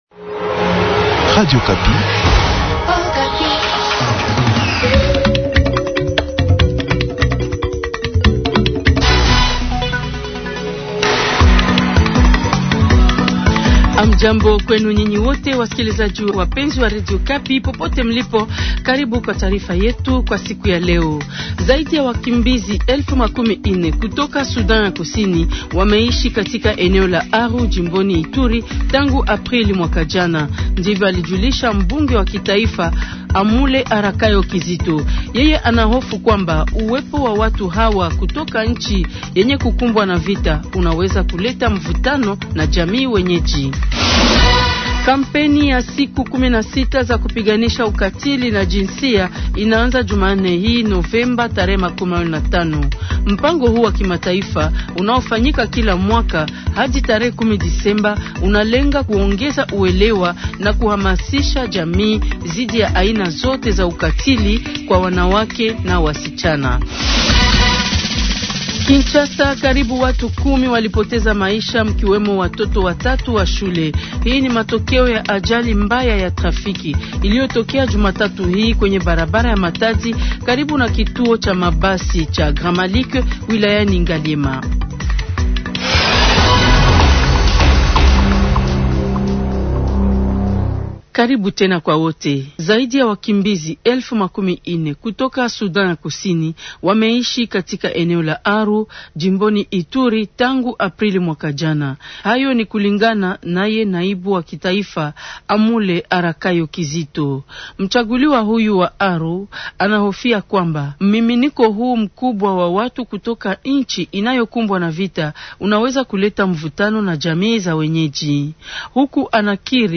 Ripoti Maalum